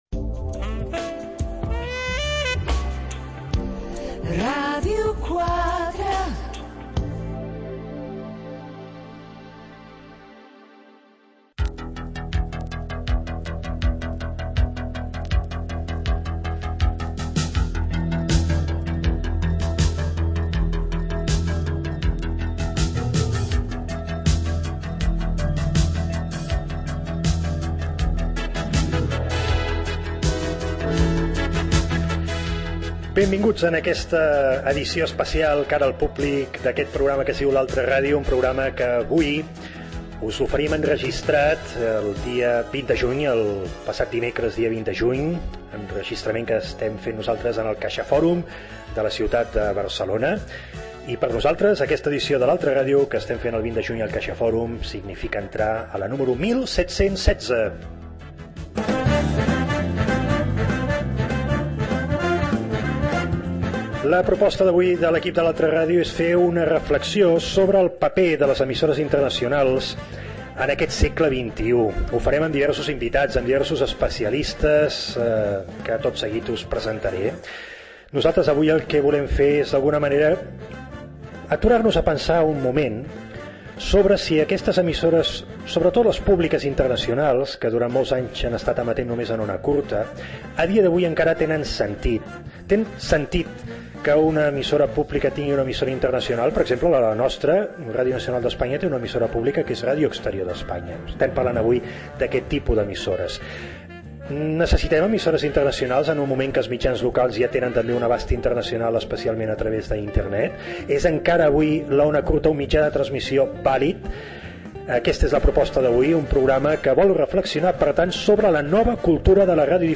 eb8389bbc2fec77a6650e1af29ab83cd60d29740.mp3 Títol Ràdio 4 Emissora Ràdio 4 Cadena RNE Titularitat Pública estatal Nom programa L'altra ràdio Descripció Espai fet al Caixa Fòrum de Barcelona i dedicat a les emissores internacionals al segle XXI. Indiucatiu de l'emissora, sintonia, presentació, equip i agraïments.